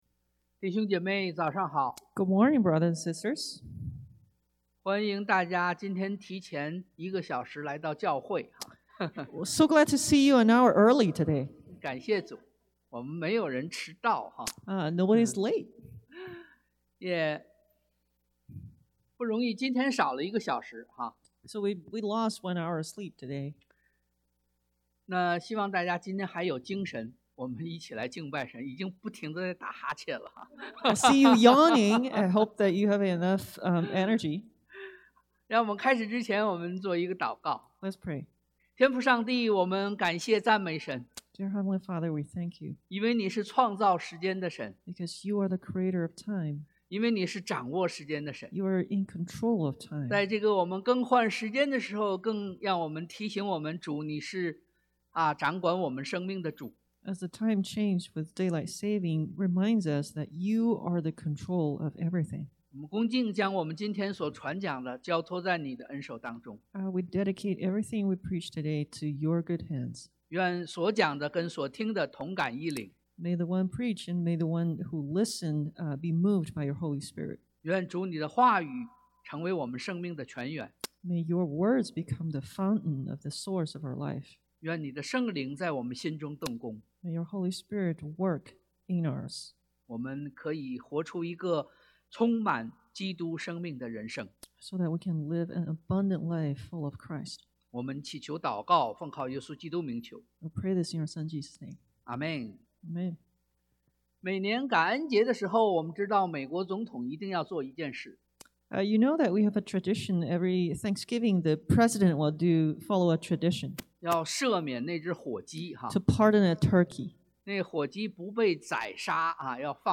約John 8:1-11 Service Type: Sunday AM Jesus dealt with hostility 耶穌面對敵意 Forgive and be forgiven 寬恕與被寬恕 Hand out forgiveness 給予寬恕 « 2024-03-03 Why Don’t You Notice a Log in Your Eye?